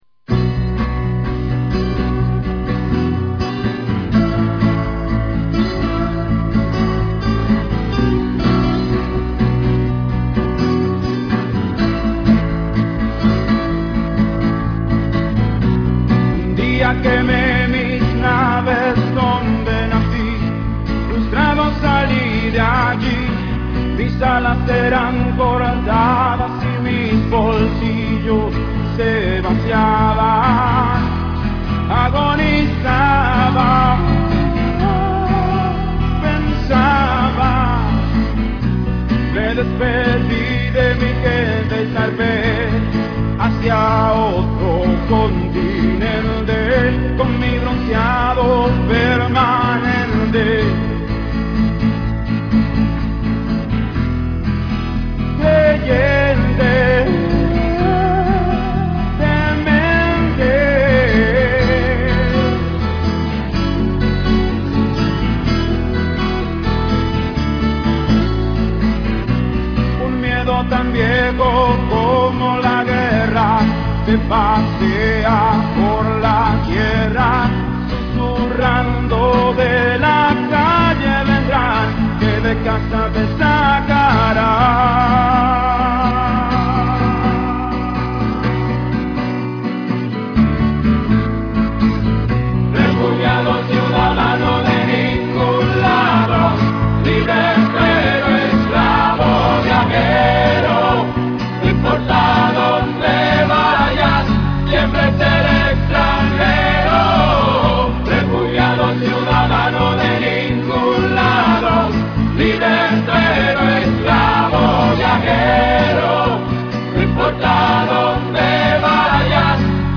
Rock Dominicano